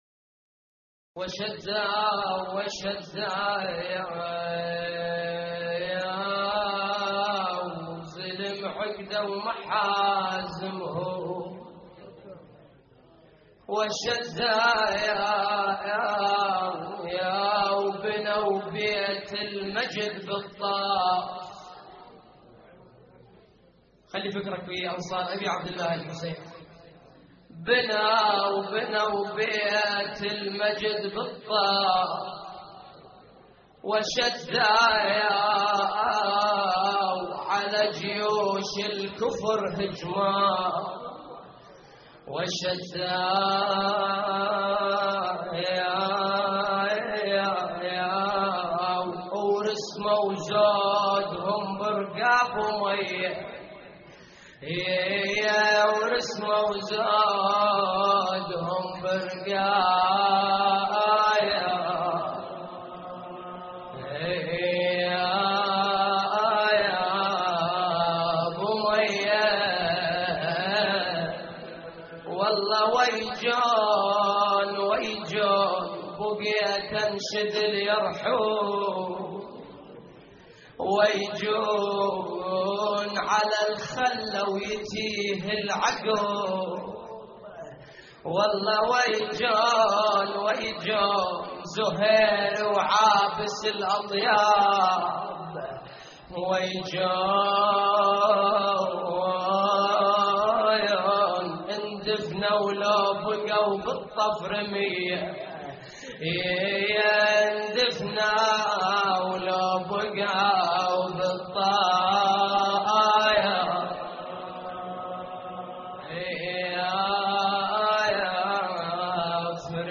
اللطميات الحسينية
موقع يا حسين : اللطميات الحسينية وشدو زلم عقدو محازمهم وشدو (نعي) - 6محرم1426هـ لحفظ الملف في مجلد خاص اضغط بالزر الأيمن هنا ثم اختر (حفظ الهدف باسم - Save Target As) واختر المكان المناسب